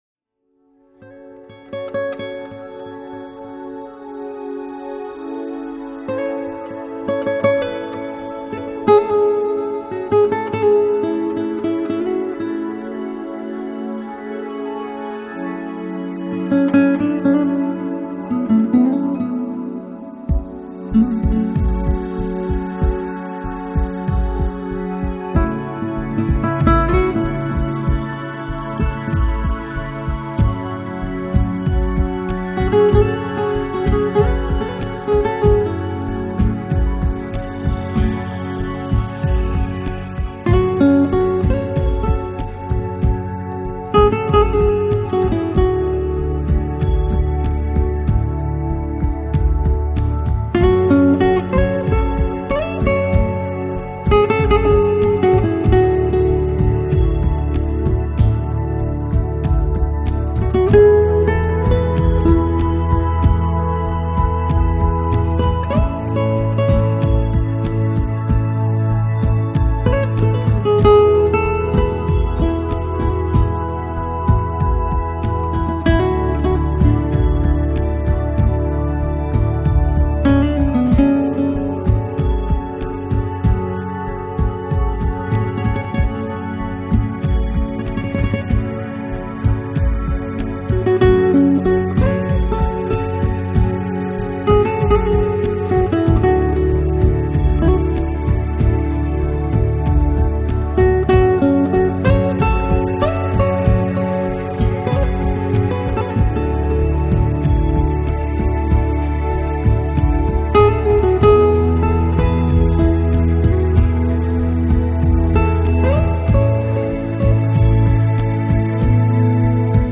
熟悉的旋律重温回忆中的浪漫，悠扬的音符盘旋在寂静的空中。